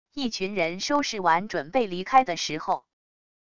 一群人收拾完准备离开的时候wav音频